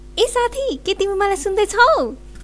girltalk.ogg